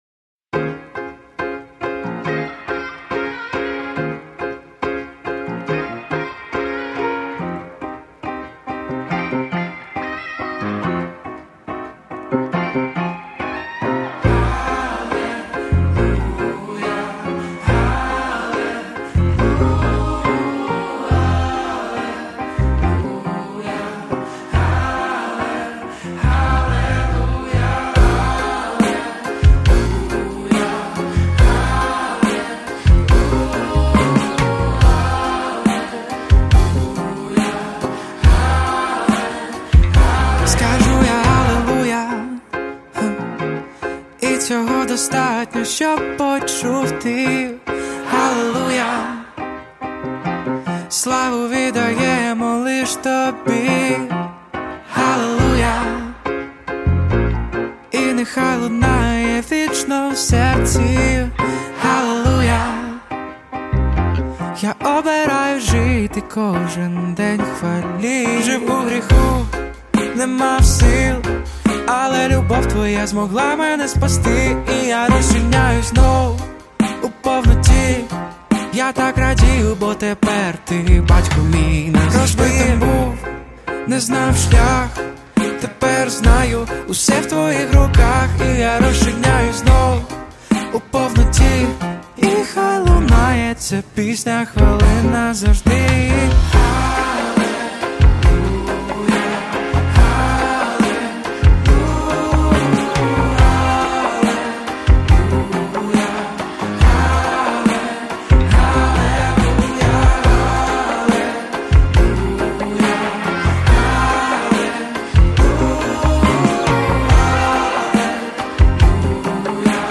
1604 просмотра 1383 прослушивания 291 скачиваний BPM: 143